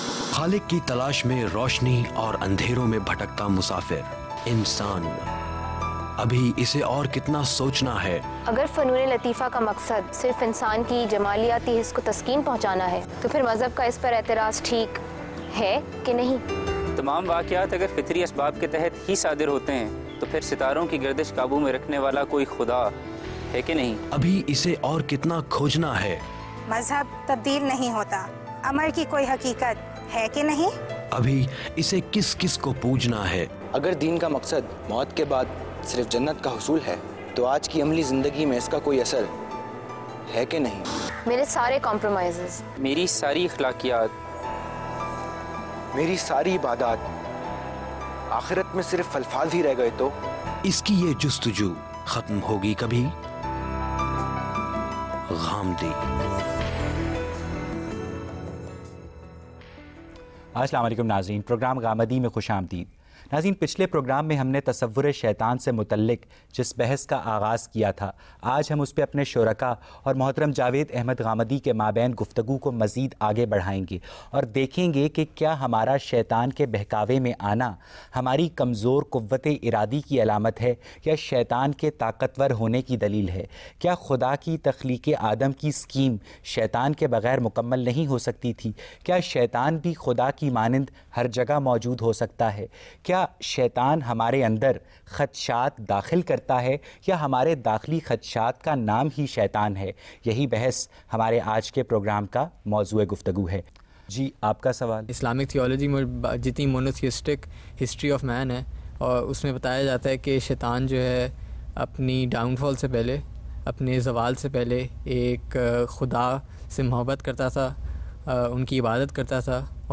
Questions and Answers on the topic “The concept of Satan” by today’s youth and satisfying answers by Javed Ahmad Ghamidi.